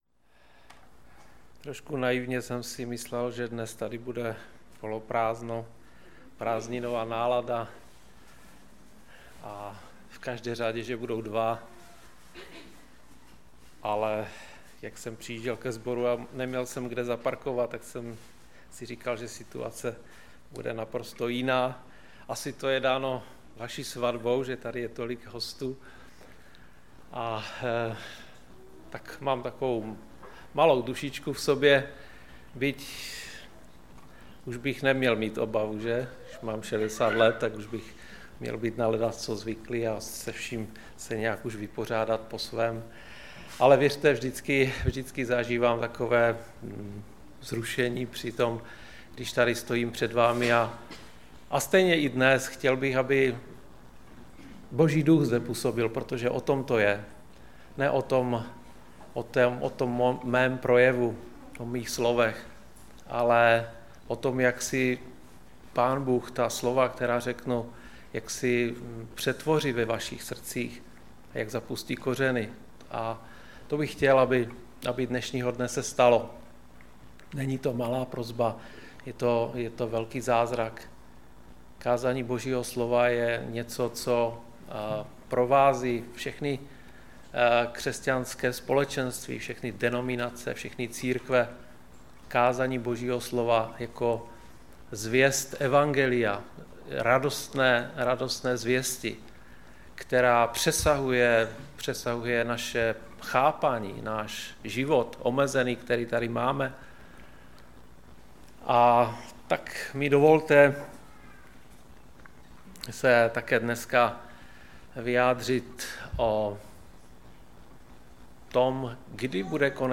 Kázání
ve sboře Ostrava-Radvanice.